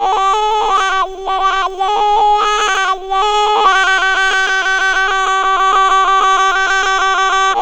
CAMBODPIPE-R.wav